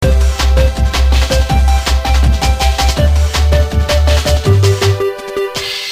happy breakbeat track.